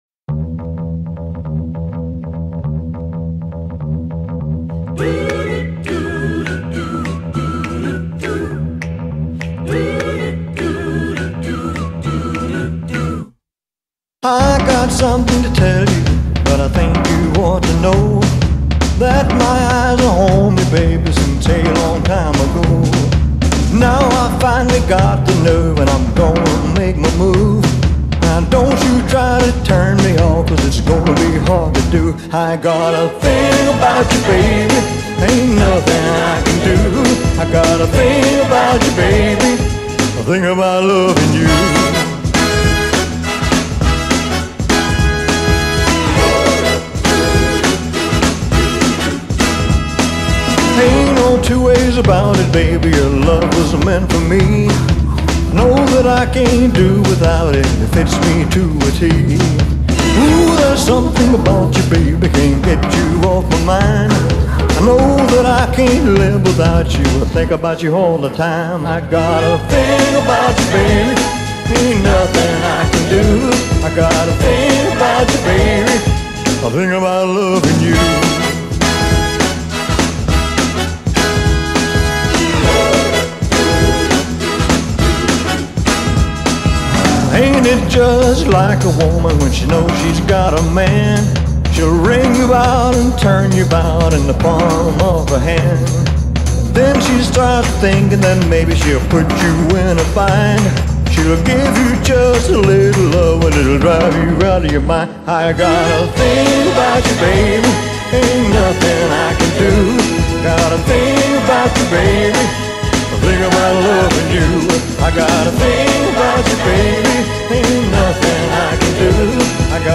Following are the professional, recorded versions.